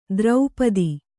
♪ draupadi